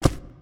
ui_interface_18.wav